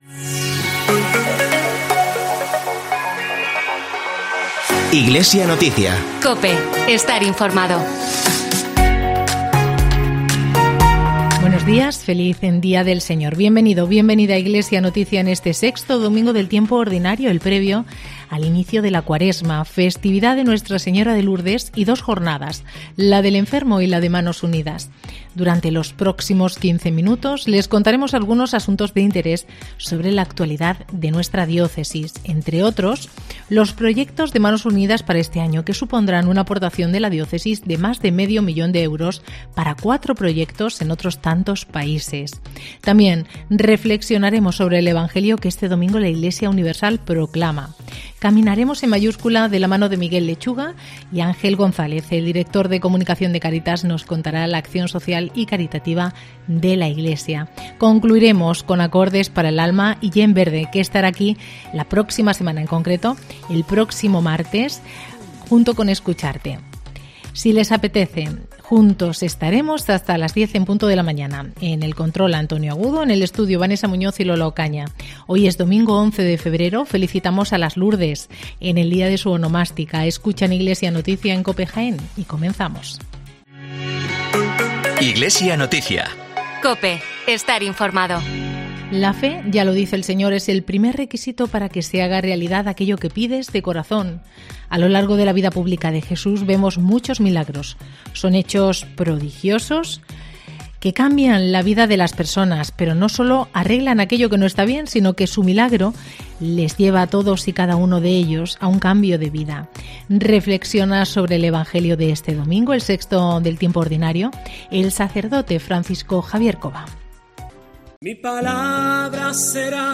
informativo Iglesia Noticia